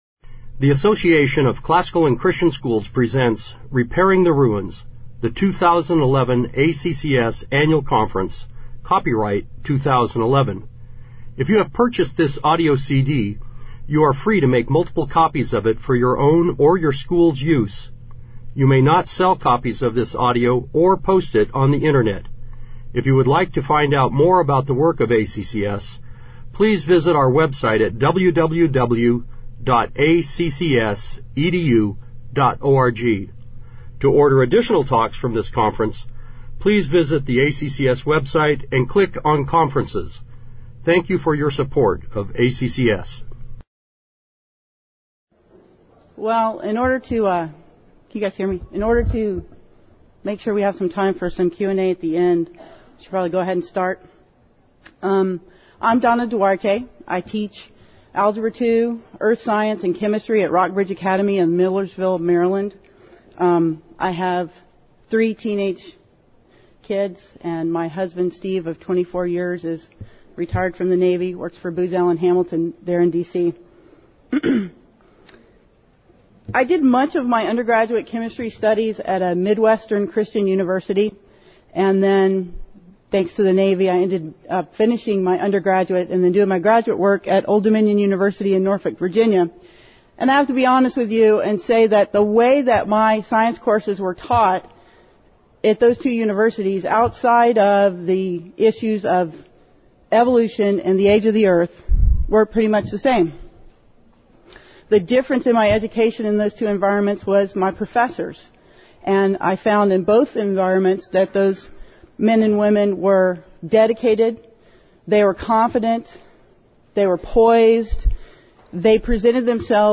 2011 Workshop Talk | 1:01:56 | All Grade Levels, Science
The Association of Classical & Christian Schools presents Repairing the Ruins, the ACCS annual conference, copyright ACCS.